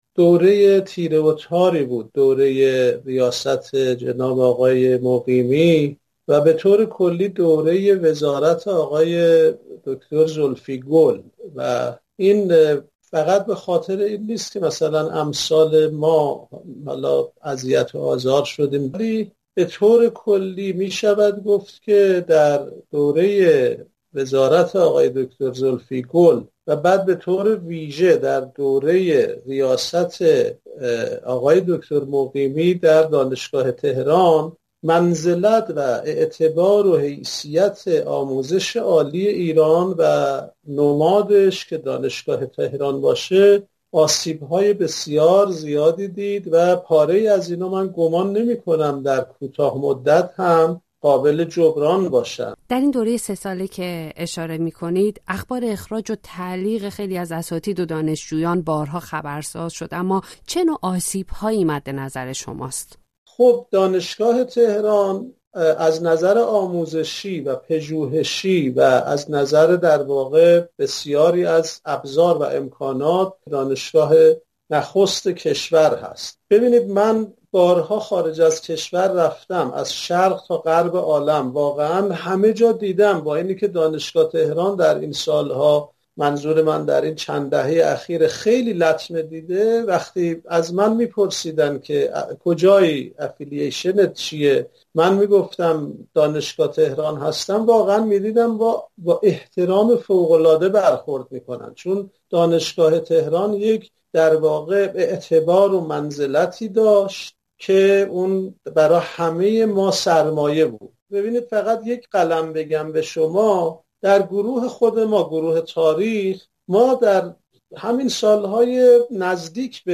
در گفتگو با رادیوفردا